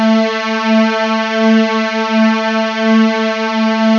SynthPad(2)_A3_22k.wav